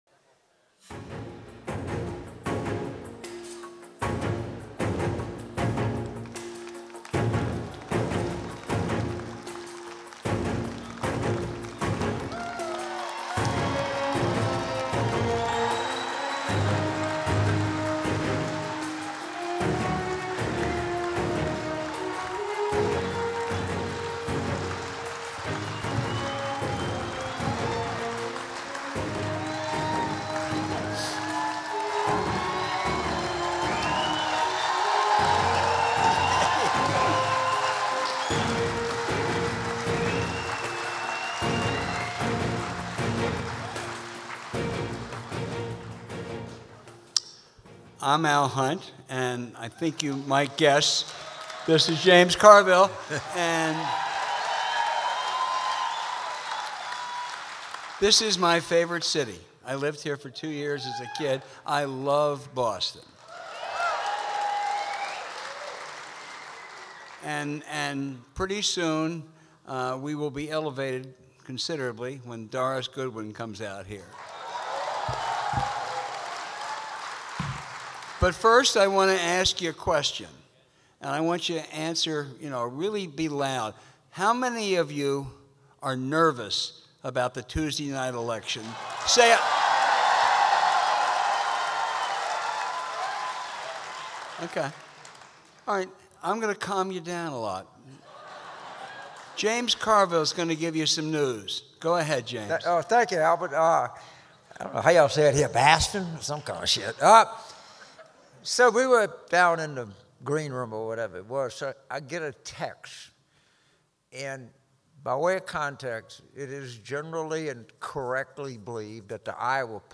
James and Al Live from The Shubert Theatre in Boston with guest Doris Kearns Goodwin.